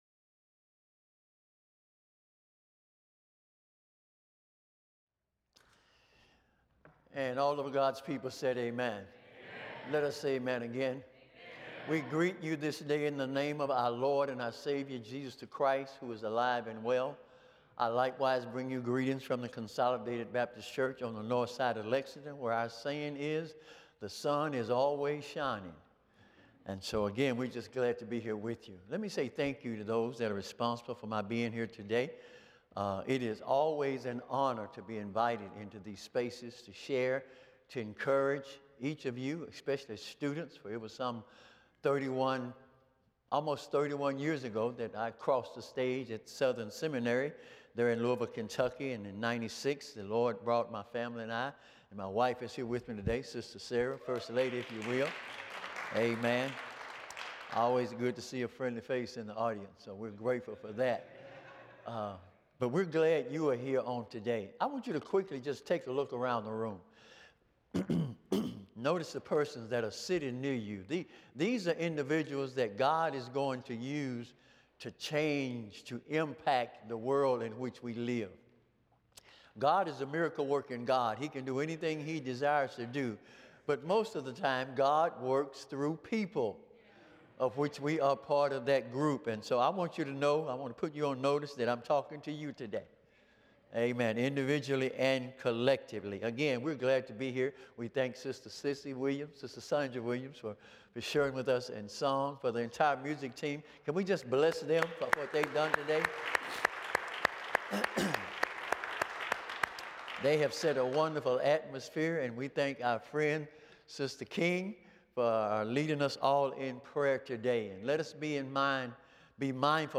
The following service took place on Thursday, February 26, 2026.